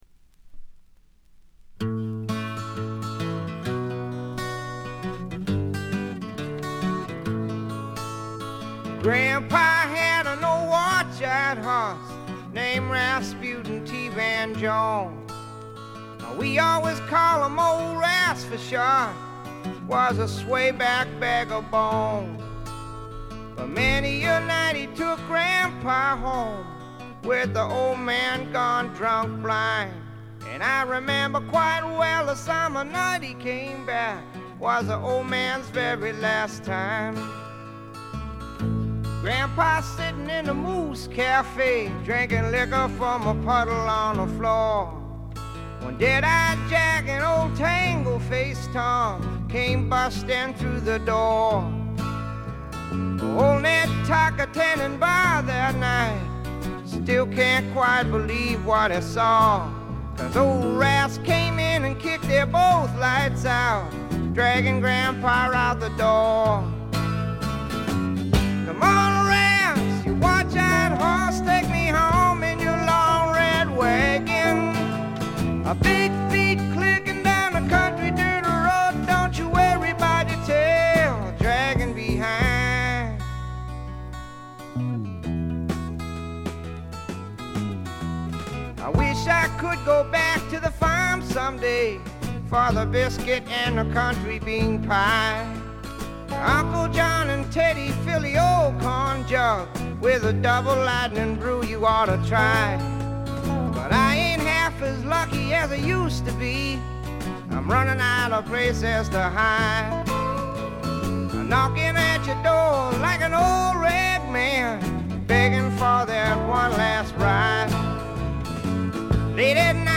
ごくわずかなチリプチ程度。
試聴曲は現品からの取り込み音源です。
Vocals, Guitar, Harmonica
Dobro, Guitar
Keyboards
Bass
Drums